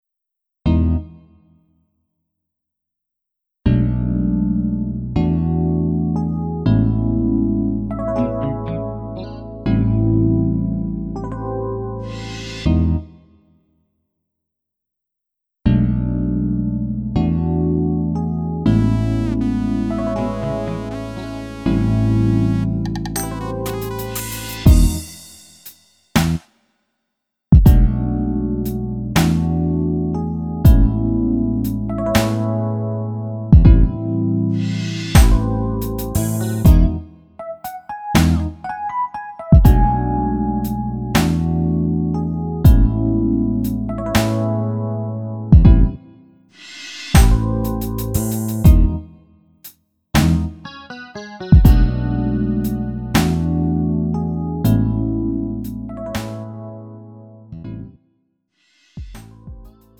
음정 -1키 3:41
장르 구분 Lite MR